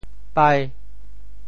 俳 部首拼音 部首 亻 总笔划 10 部外笔划 8 普通话 pái 潮州发音 潮州 bai5 文 中文解释 俳〈名〉 (形声。
pai5.mp3